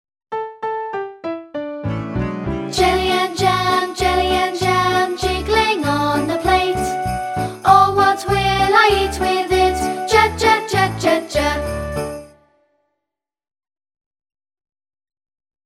每个发音都配有一个用著名曲调填词的短歌和动作图示。